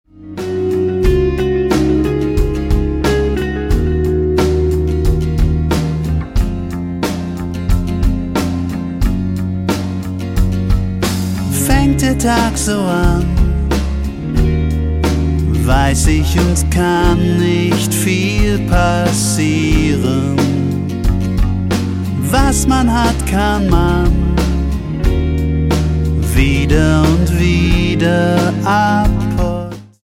zu Herzen gehende Melancholie